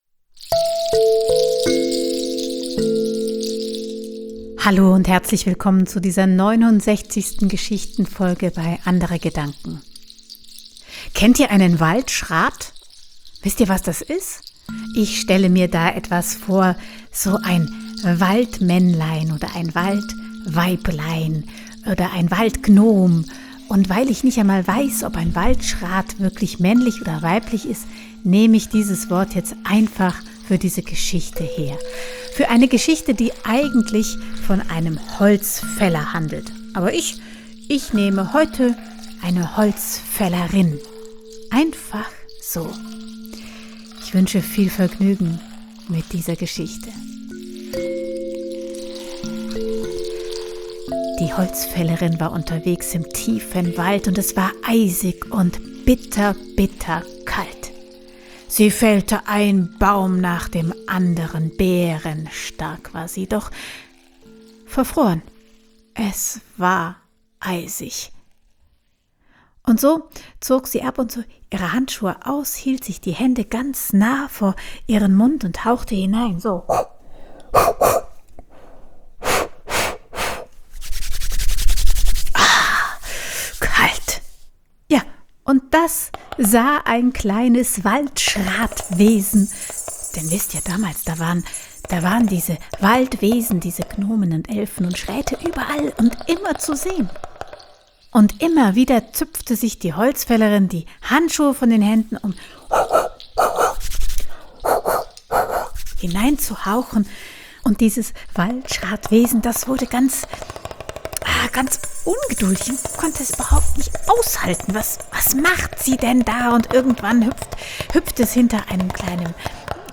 frei erzählte Geschichten, Musik, Inspiration